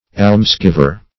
Almsgiver \Alms"giv`er\ ([add]mz"g[i^]v`[~e]r), n.